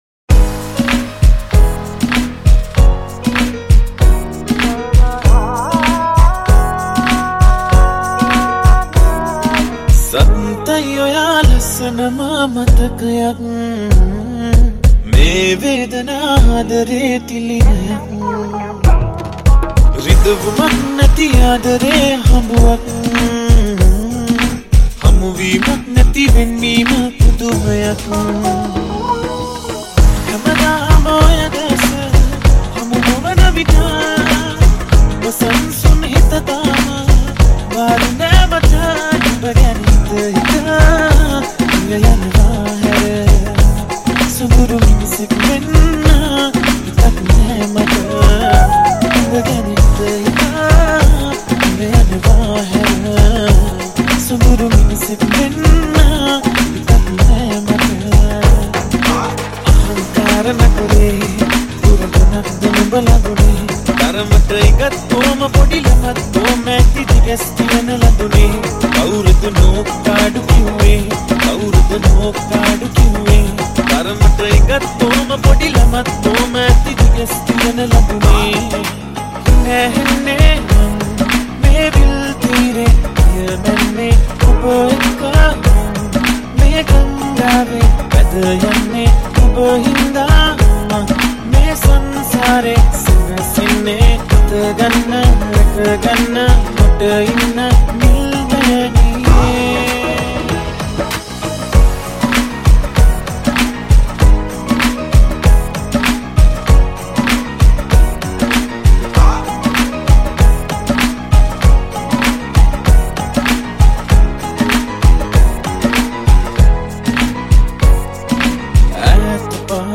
Sinhala Remix Song